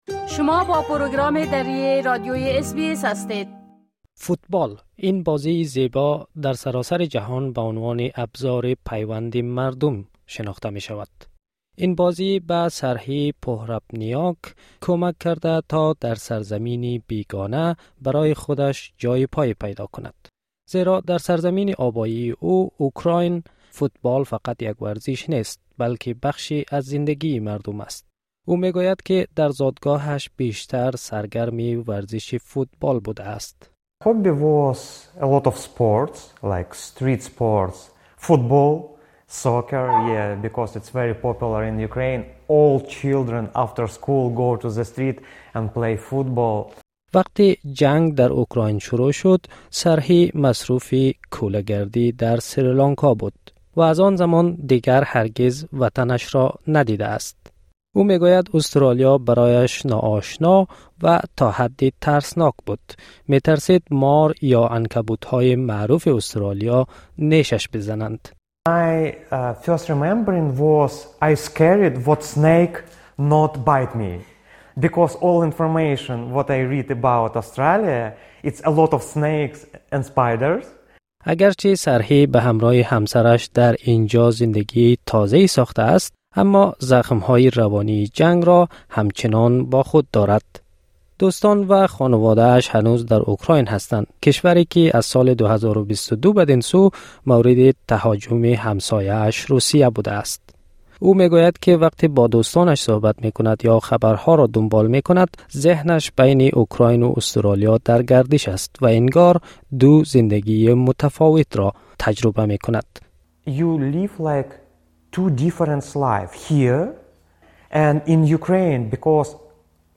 شهر پرت، پایتخت ایالت استرالیای غربی، میزبان مسابقاتی است که پناهندگان را از جوامع مختلف دور هم جمع می‌کند تا با به نمایش گذاشتن توانایی‌های خود در میدان فوتبال، بین فرهنگ‌های شان پل بزنند. در این گزارش به سرگذشت یکی از بازیکنان جام آزادی می‌پردازیم که از کشورش به وسیله جنگ آواره شده است.